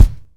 Kick (73).wav